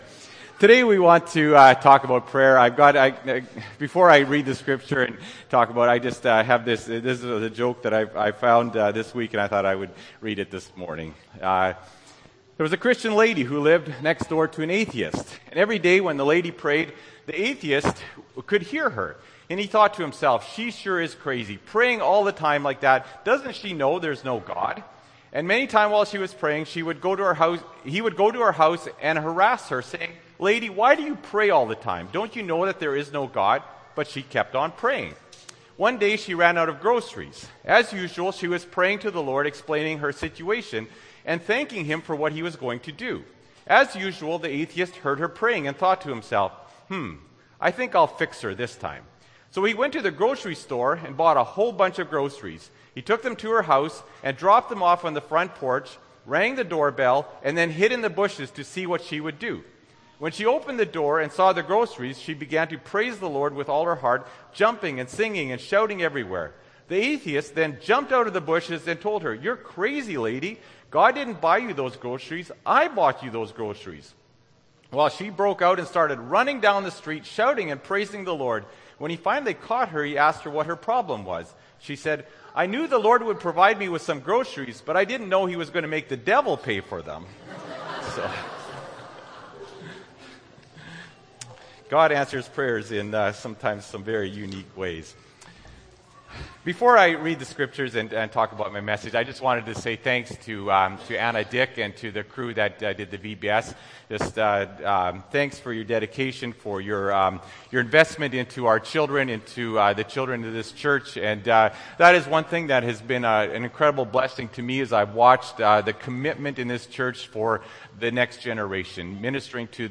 Aug. 3, 2014 – Sermon